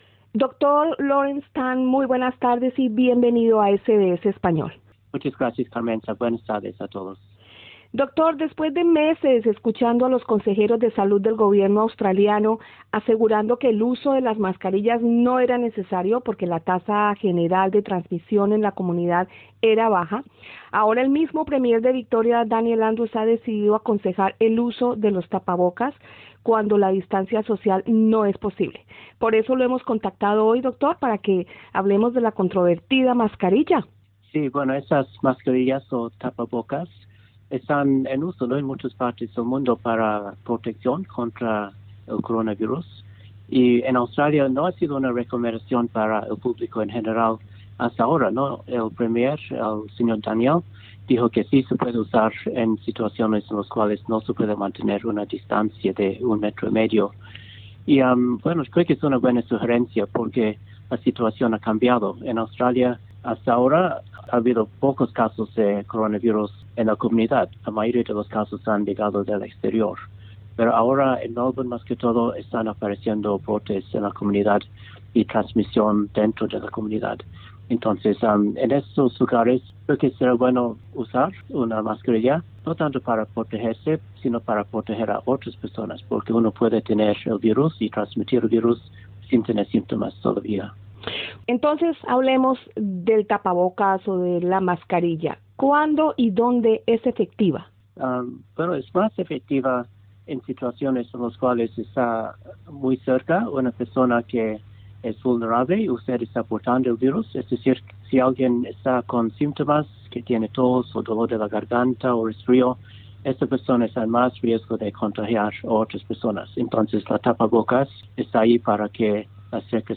En entrevista con SBS español